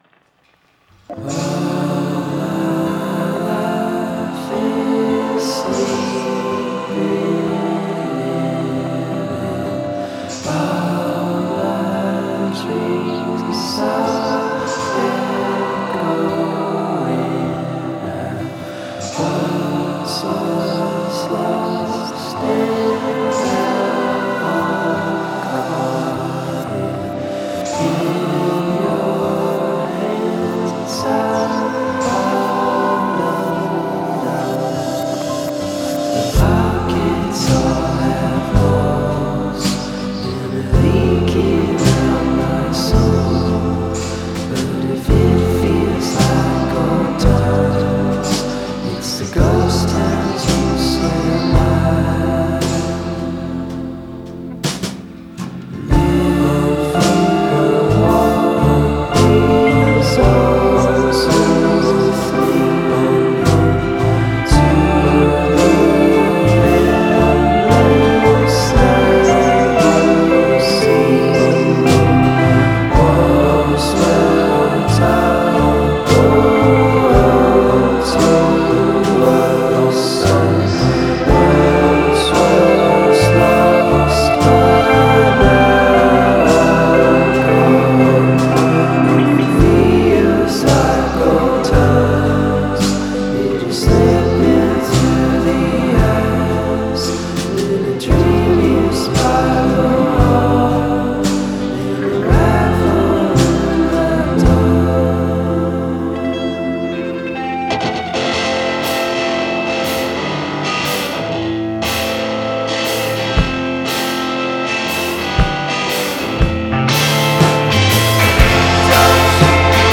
” isn’t your typical rock number.
mesmerizing vocals